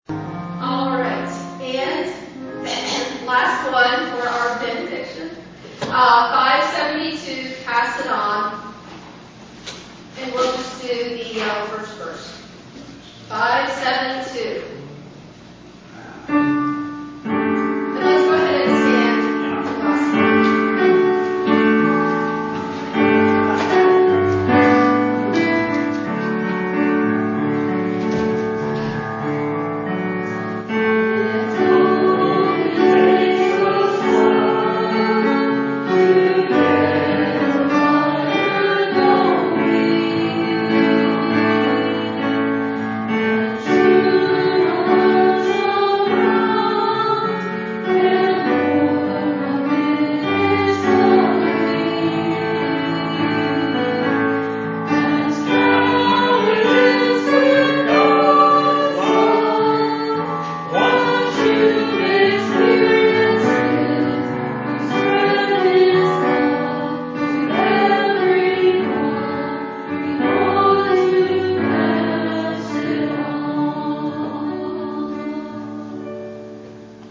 Hymn Sing
It has been said that each hymn contains a sermon/message, so consider taking some time to read all of the verses to reveal the full message of the hymn...Each recording includes statements made before the hymn is sung...as well as any comments made after the singing of the preceding hymn.